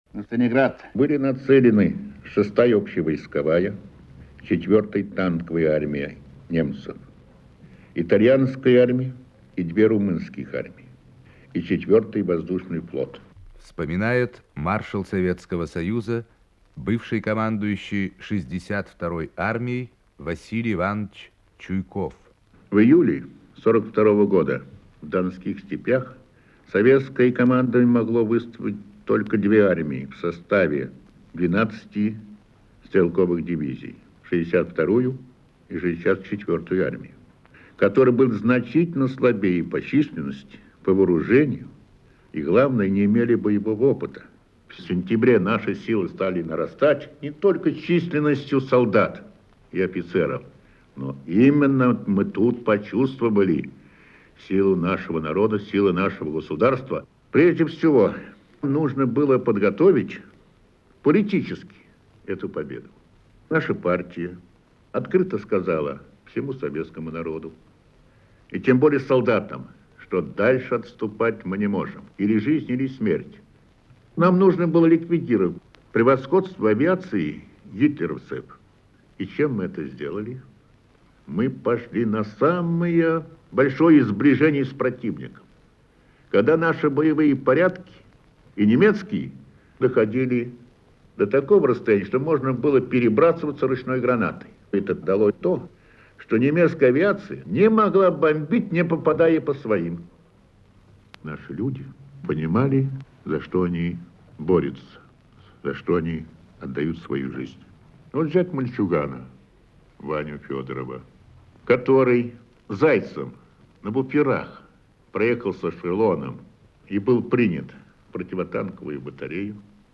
Маршал Советского Союза Василий Чуйков рассказывает о  массовом героизме советских солдат в боях за Сталинград  (Архивная запись).